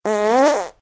toot.ogg